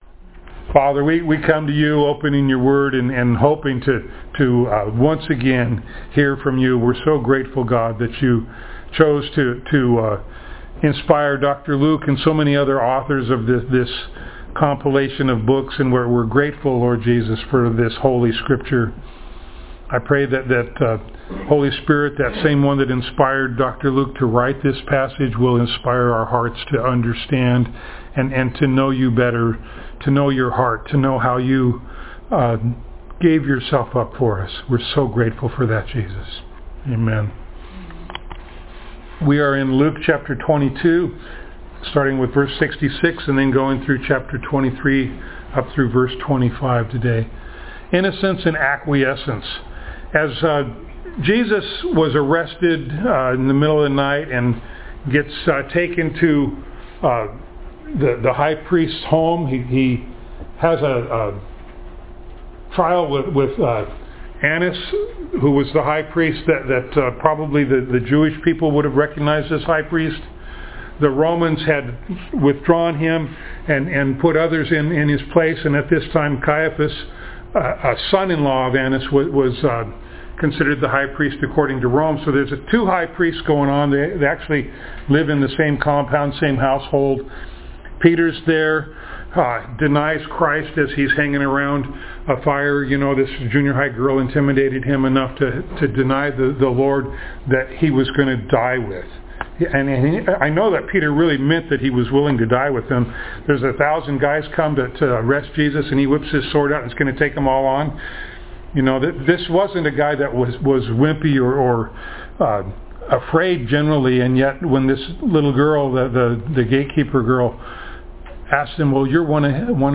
Passage: Luke 22:66-23:25 Service Type: Sunday Morning